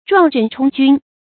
撞阵冲军 zhuàng zhèn chōng jūn
撞阵冲军发音